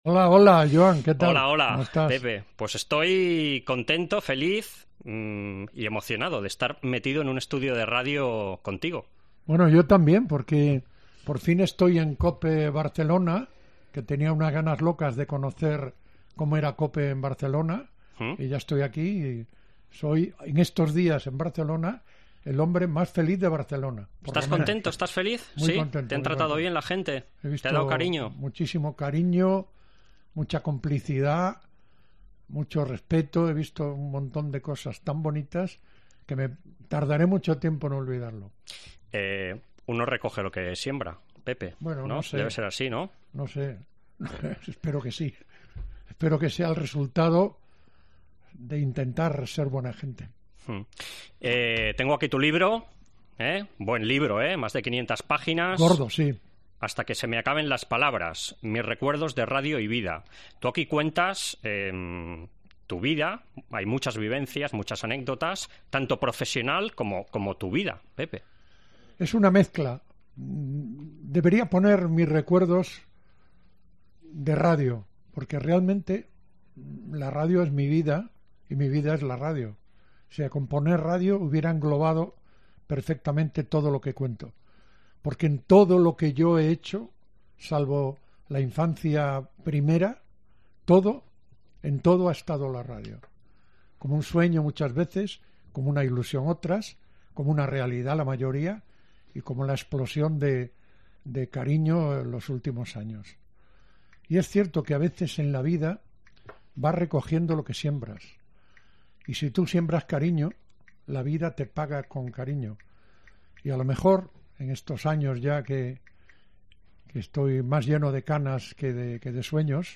El periodista visita los estudios de Cope Catalunya para presentar su libro 'Hasta que se me acaben las palabras: Recuerdos de un gallego que se enamoró de la radio'.